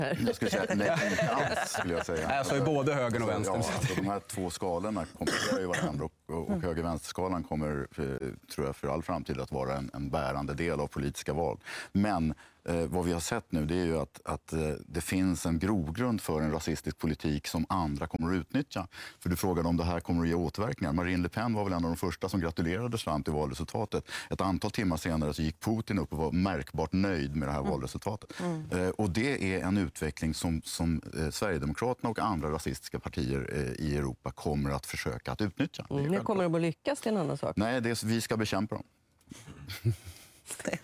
En av de flitigaste gästerna i programmets Nyhetspanel är kommunisten och Vänsterpartiets förra partiledare Lars Ohly, som inte sällan får framföra sina åsikter ganska oemotsagd.
ohly_trump.mp3